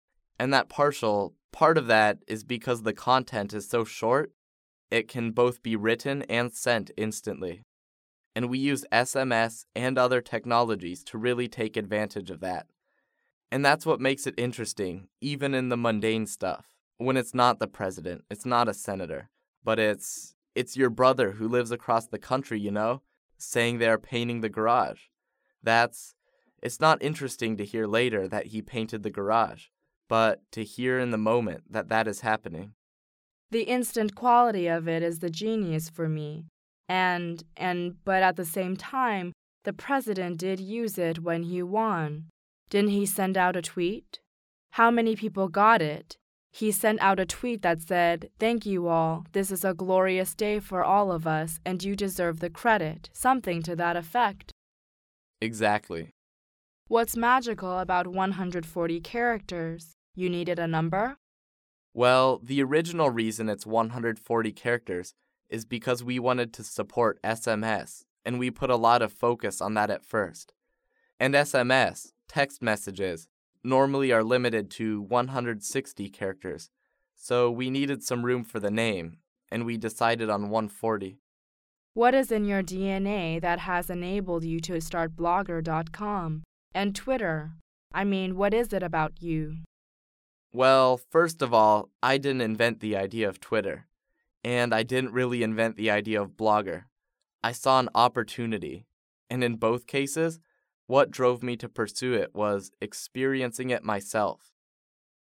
创业成功人士访谈录 第48期:我认为推特网结合了很多东西(3) 听力文件下载—在线英语听力室